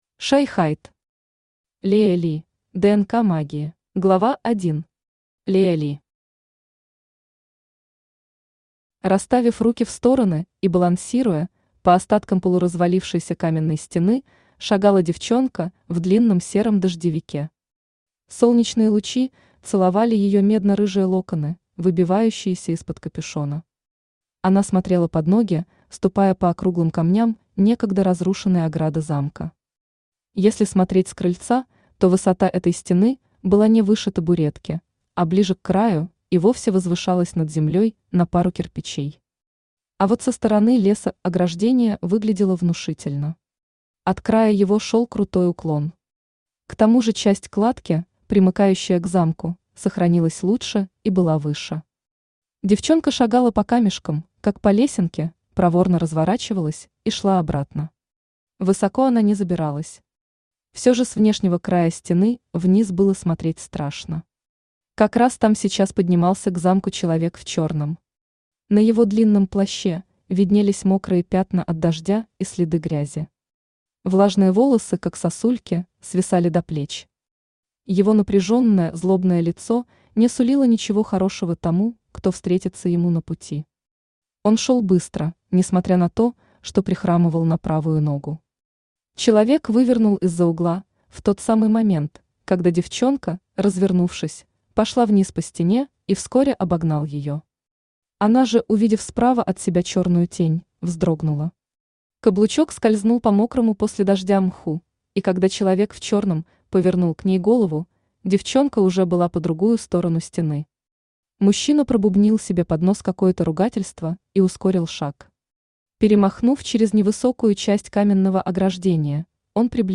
Aудиокнига Лея Ли: ДНК магии Автор Shy Hyde Читает аудиокнигу Авточтец ЛитРес. Прослушать и бесплатно скачать фрагмент аудиокниги